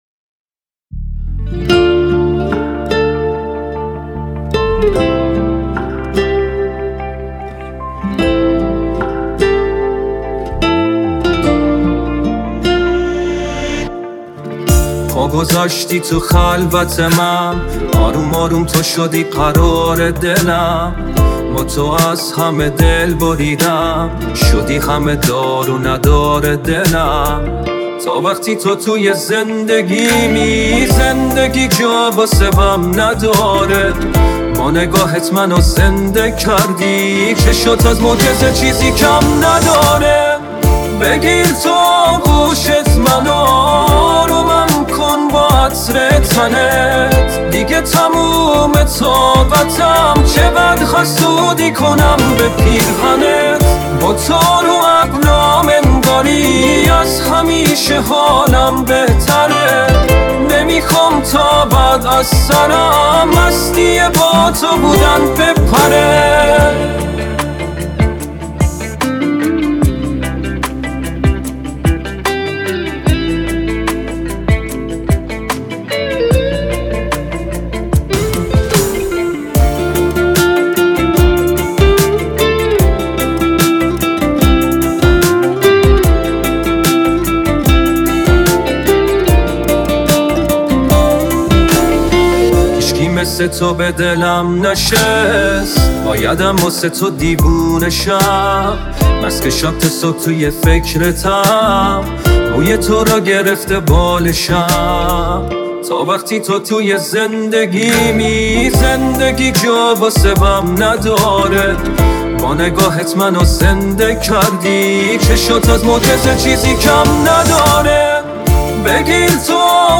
دانلود آهنگ پاپ ایرانی دانلود آهنگ جدید هوش مصنوعی